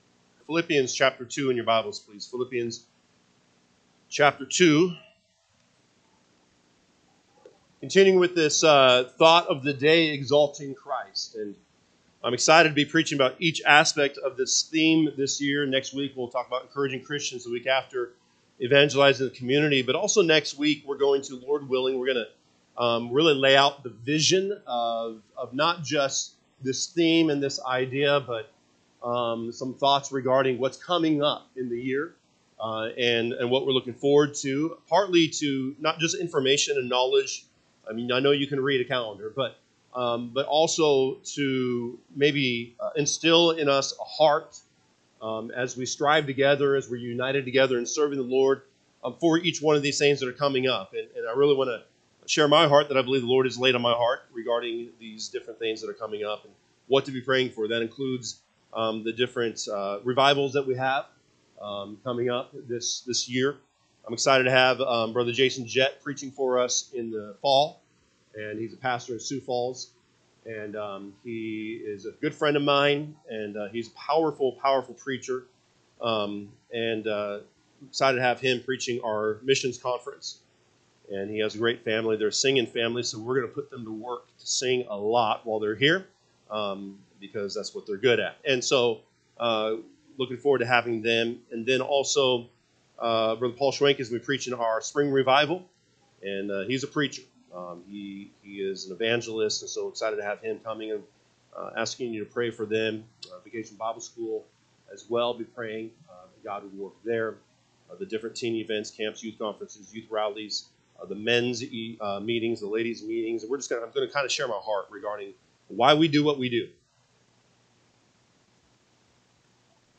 January 12, 2025 pm Service Philippians 2:5-11 (KJB) 5 Let this mind be in you, which was also in Christ Jesus: 6 Who, being in the form of God, thought it not robbery to be equal with Go…